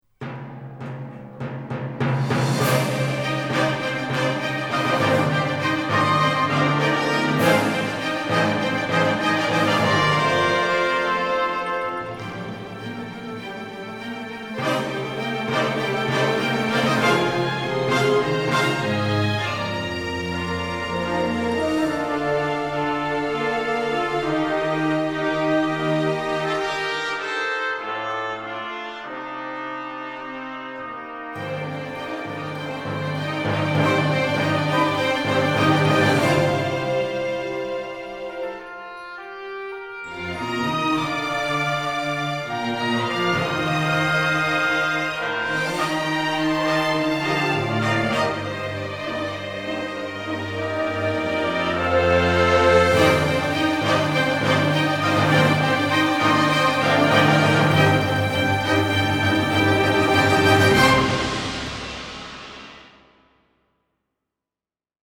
epic adventure